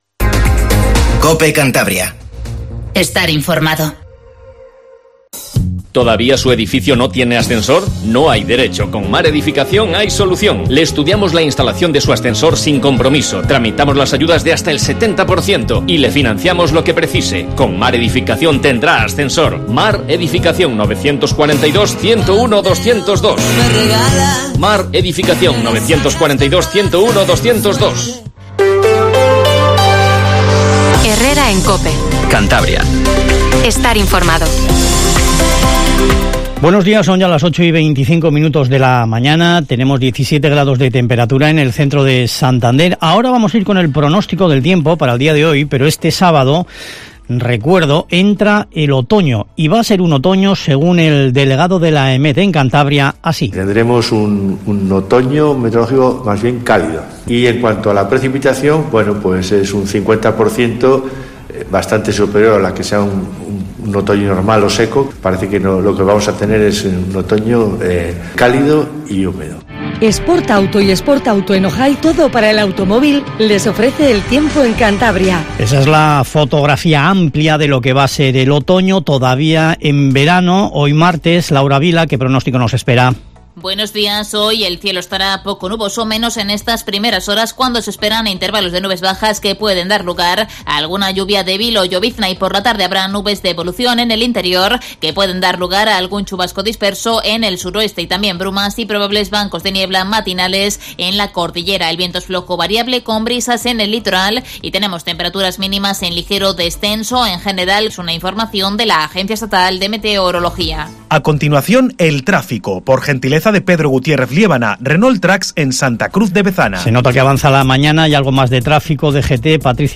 Informativo HERRERA en COPE CANTABRIA 08:24